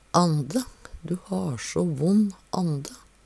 Tilleggsopplysningar brukast berre i eintal Sjå òg kallpusst (Veggli) Høyr på uttala Ordklasse: Substantiv hankjønn Kategori: Kropp, helse, slekt (mennesket) Attende til søk